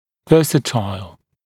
[‘vɜːsətaɪl][‘вё:сэтайл]многоцелевой, универсальный, многосторонний